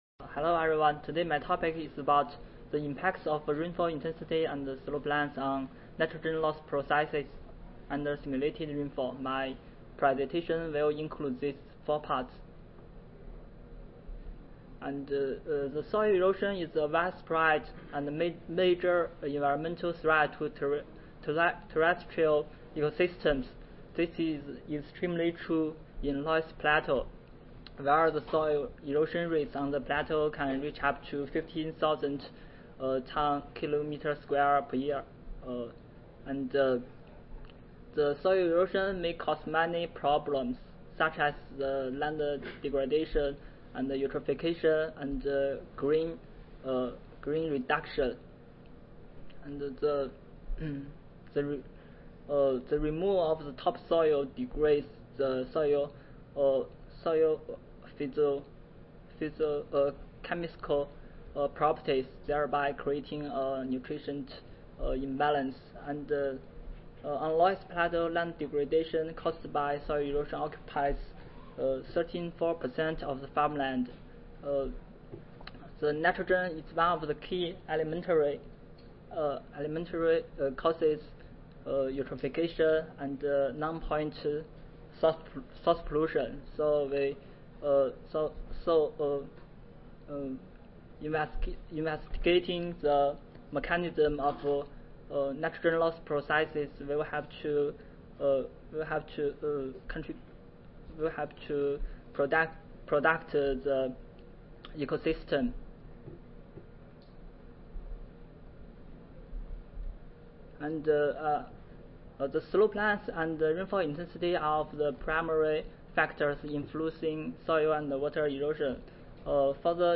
China Agricultural University Audio File Recorded Presentation